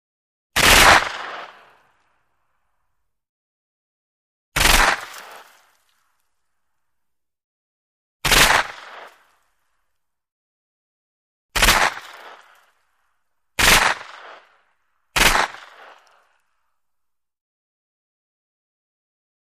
9 mm Mac-10 Automatic: Multiple Bursts; 9 mm Mac-10 Automatic Fires Multiple Bursts With Echo. Close Perspective. Gunshots.